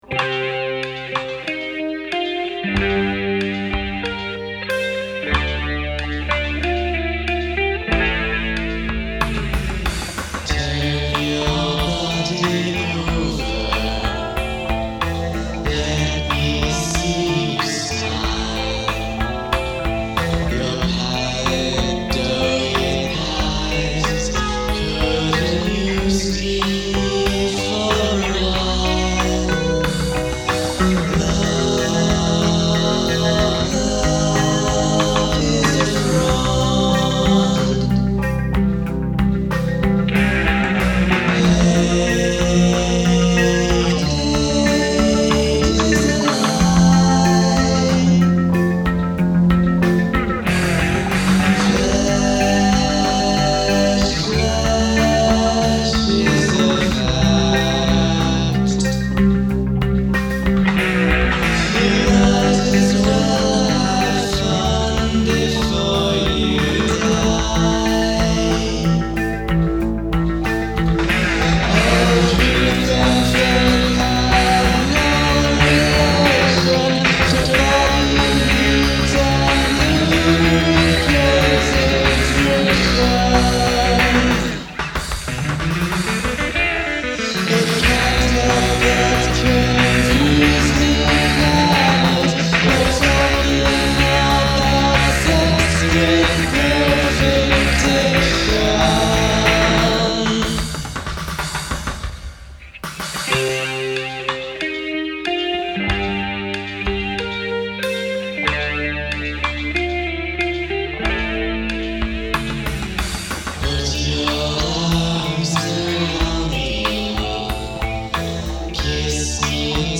4-track